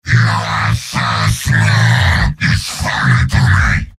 Giant Robot lines from MvM. This is an audio clip from the game Team Fortress 2 .
{{AudioTF2}} Category:Heavy Robot audio responses You cannot overwrite this file.
Heavy_mvm_m_taunts02.mp3